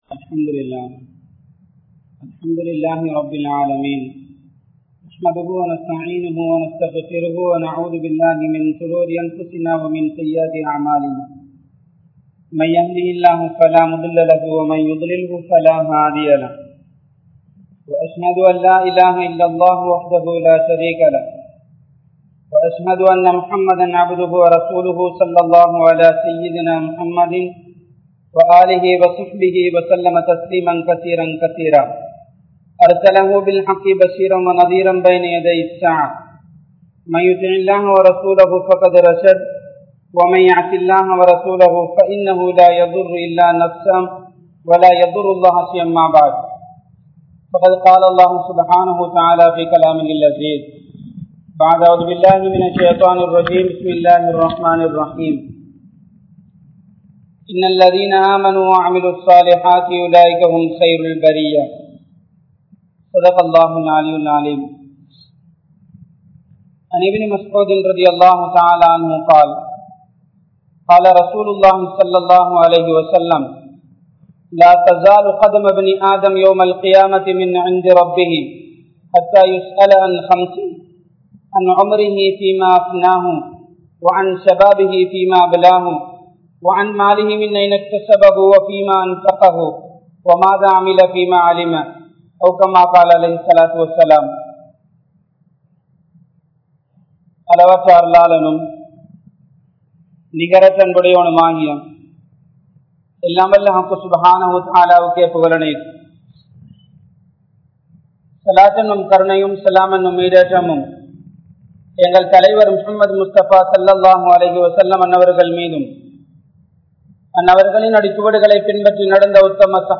Ungal Pillaihal Nallavarhala? | Audio Bayans | All Ceylon Muslim Youth Community | Addalaichenai
Colombo 12, Aluthkade, Muhiyadeen Jumua Masjidh